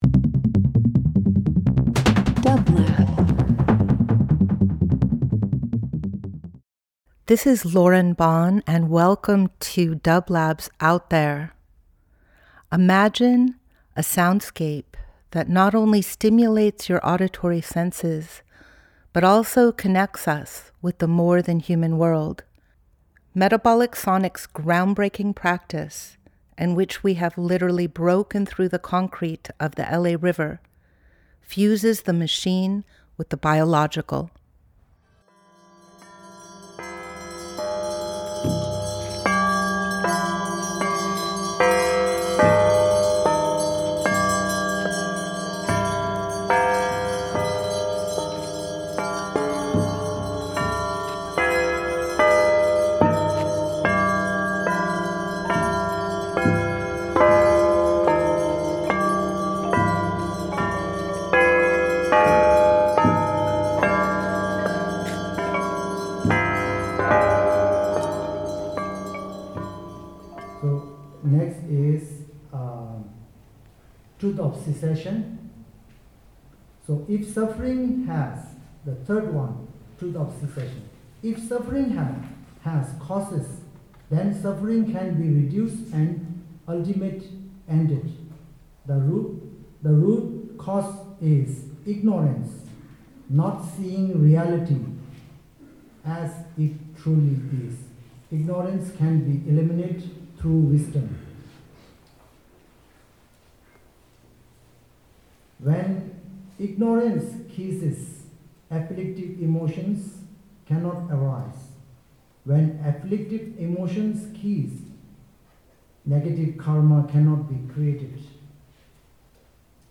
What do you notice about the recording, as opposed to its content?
Each week we present field recordings that will transport you through the power of sound.